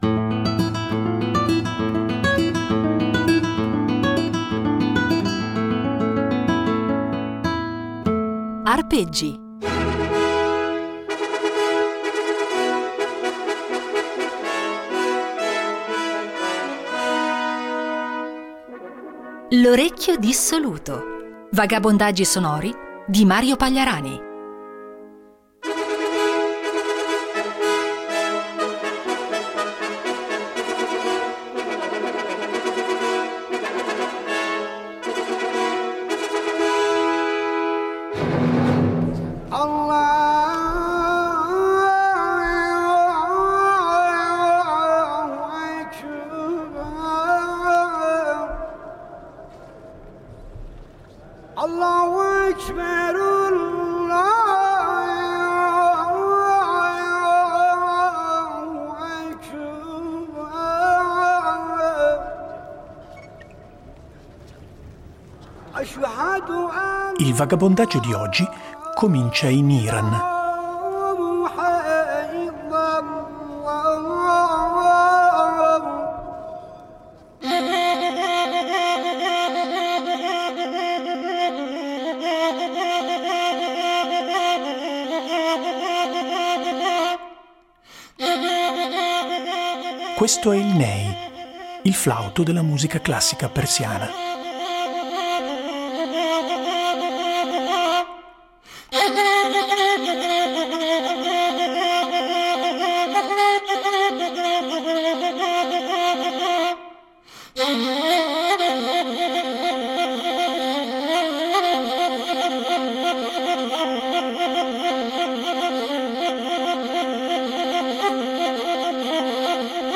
Un invito a perdersi tra suoni, rumori, e ascolti immaginifici che si rincorrono senza meta.